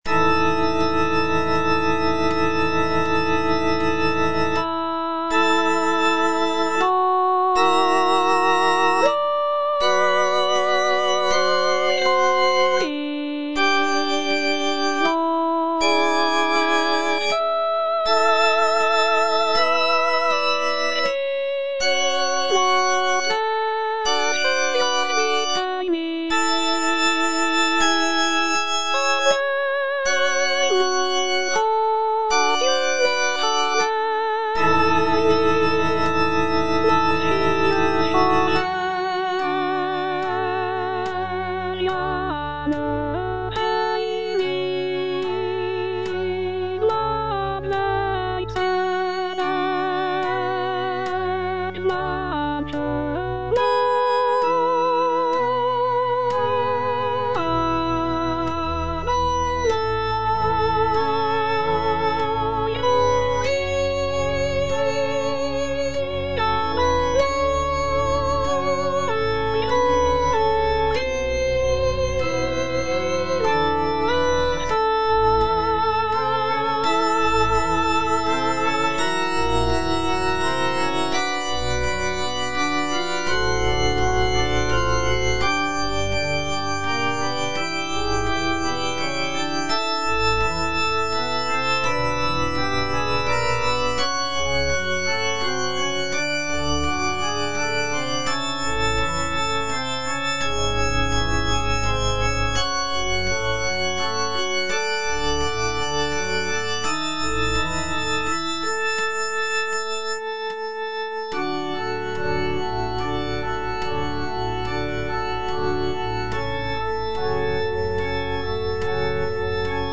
tenor I) (Voice with metronome